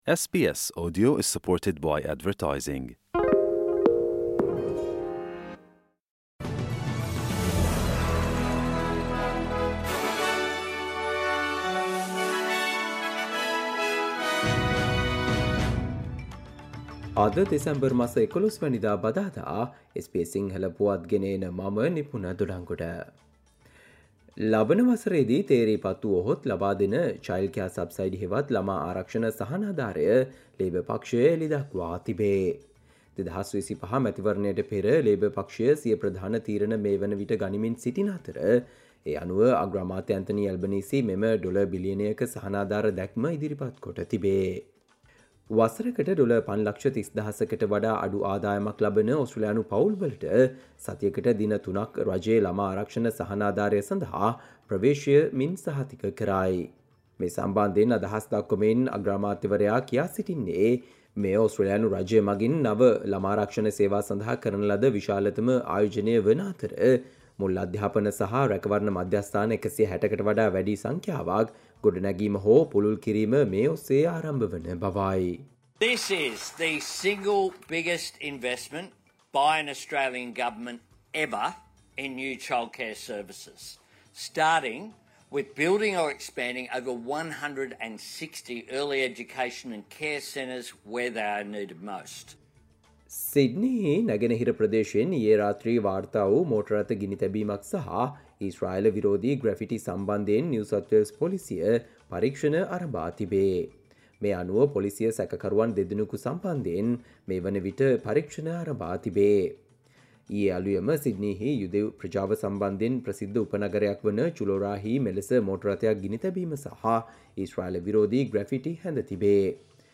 ඕස්ට්‍රේලියාවේ පුවත් සිංහලෙන්, විදෙස් සහ ක්‍රීඩා පුවත් කෙටියෙන් දැනගන්න, සවන්දෙන්න, අද - 2024 දෙසැම්බර් 11 බදාදා SBS සිංහල Newsflash